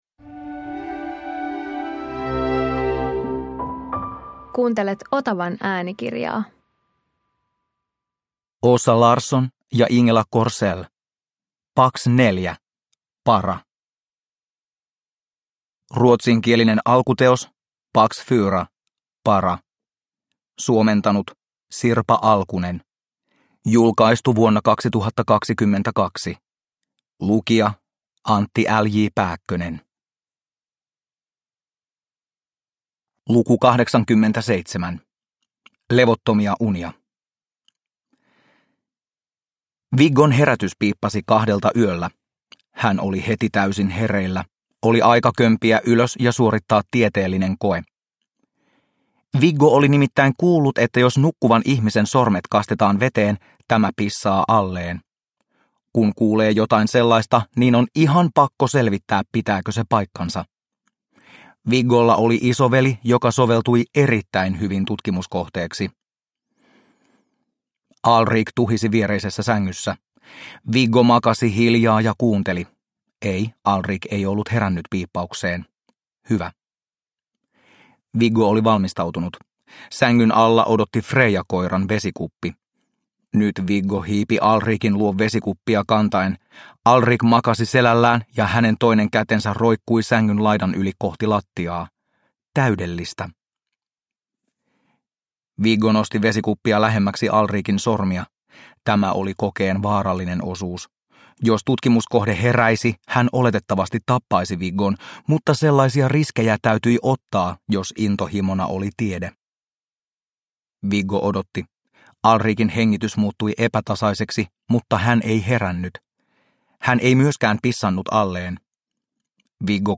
Pax 4 - Para – Ljudbok – Laddas ner